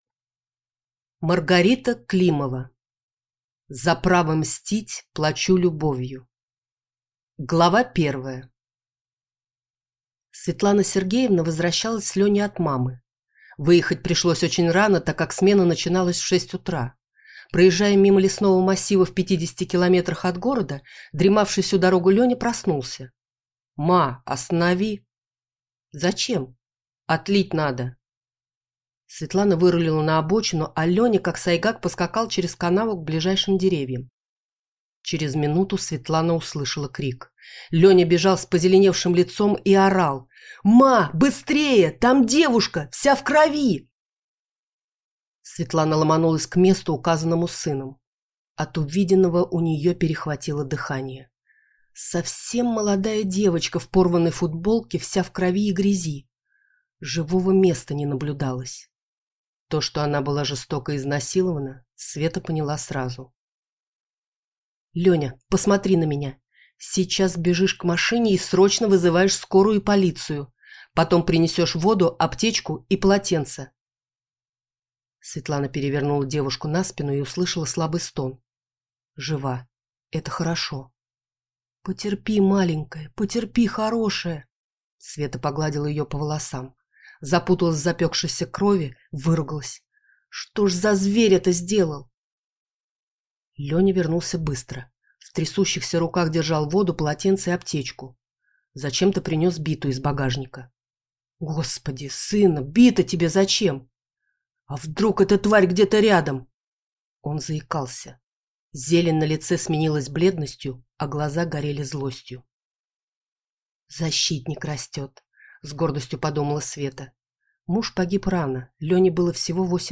Аудиокнига За право мстить плачу любовью | Библиотека аудиокниг